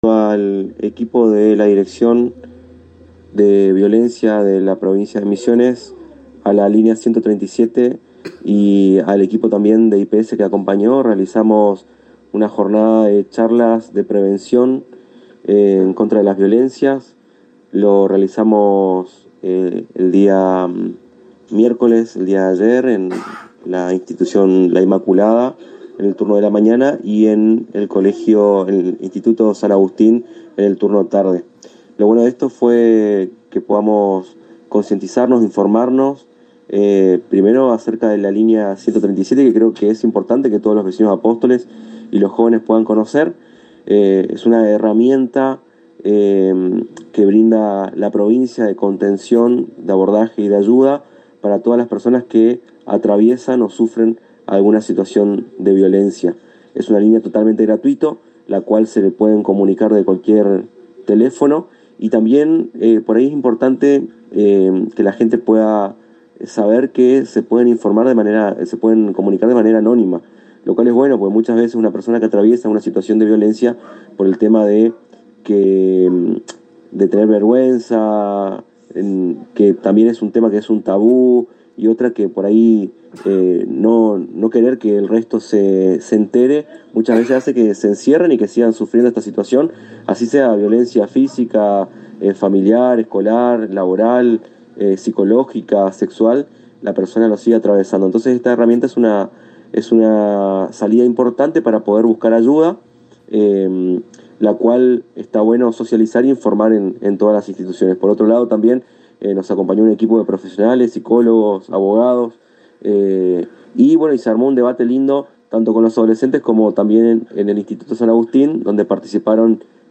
En diálogo exclusivo con la ANG, el Director de la Juventud Aldo Muñoz, comentó el trabajo que realizaron junto a la Dirección Gral. Contra la Violencia y el Departamento de Salud Mental del I.P.S.M visitaron Establecimientos Educativos de la Ciudad generando conciencia sobre el uso de la línea 137 y las distintas acciones que genera el Gobierno en contra de todos los tipos de violencia.